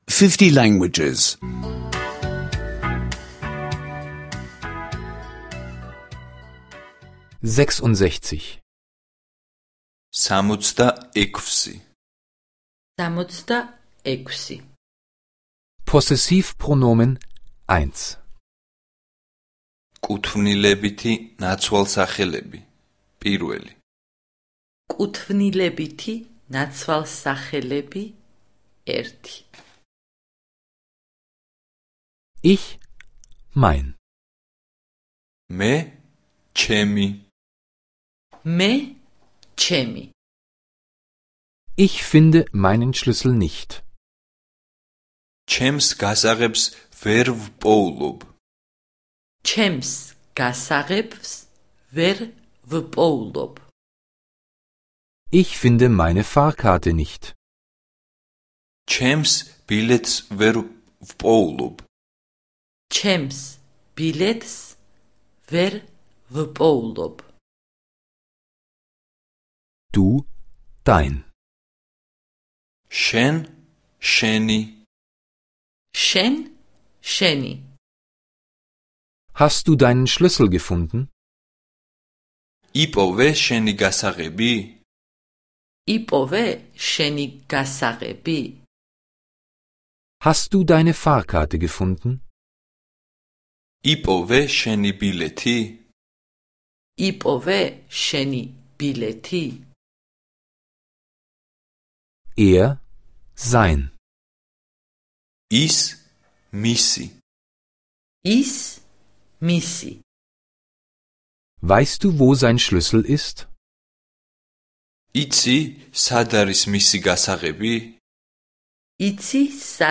Georgische Audio-Lektionen, die Sie kostenlos online anhören können.